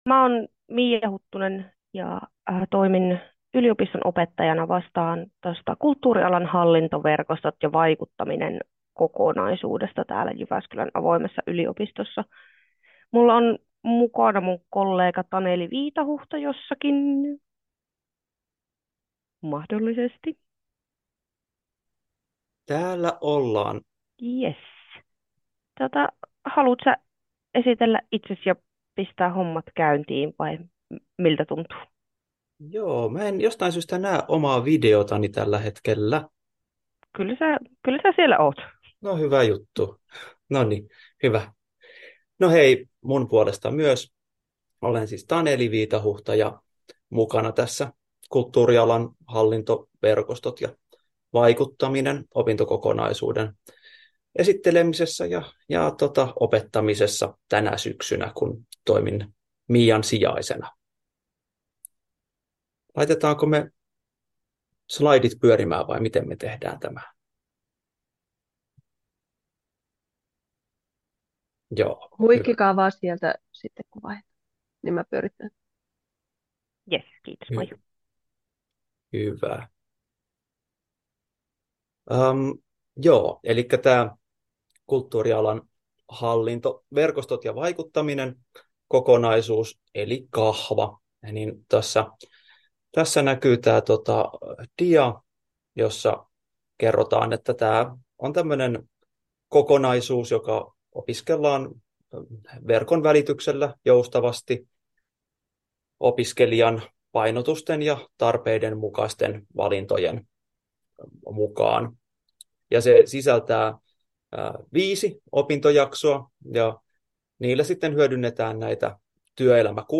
Aloita avoimessa -webinaari